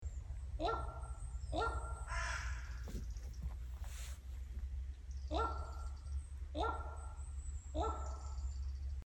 Help identify bird sound
I recorded this sound in the Highlands in September.
Bird-sound.mp3